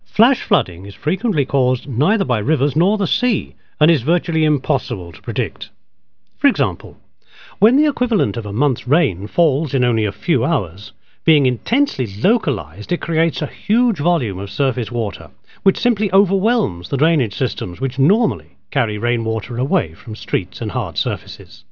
Teeafit Sound & Vision has a sophisicated audio facility, idea for the recording of spoken-word material.
The Environment Agency's Flood Awareness programme is promoted through a CD-Rom. This is one of the voiceover files.